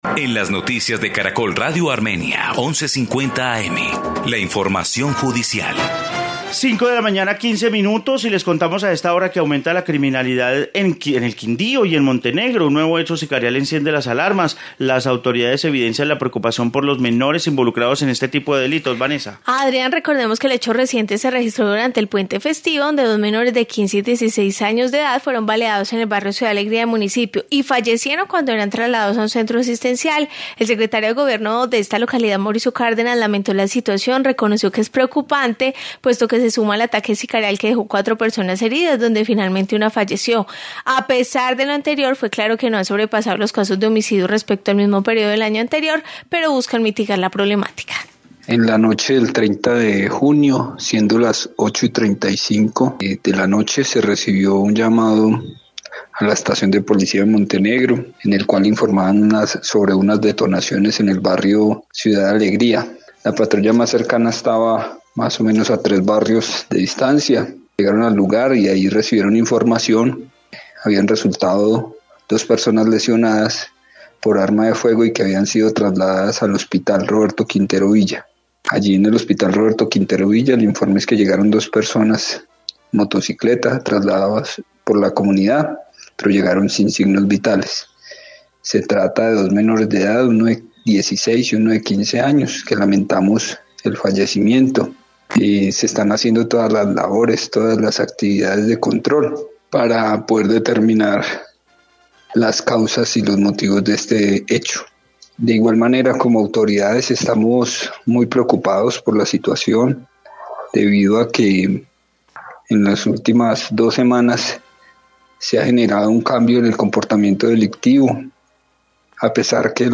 Informe homicidios